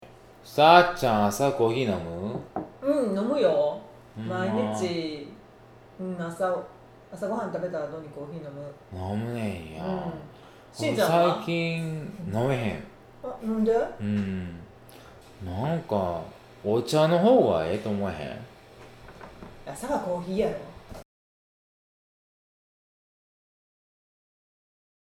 male female middle age casual